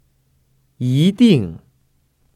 [yídìng] 이띵